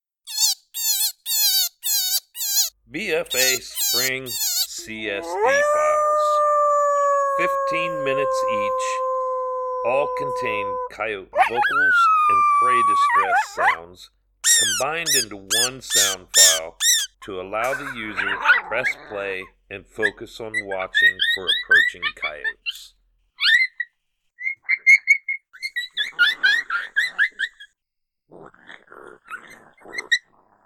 Each BFA Spring CSD File is made up of our most popular Coyote Howls, Coyote Social Vocalizations, Coyote fights and Prey Distress Files.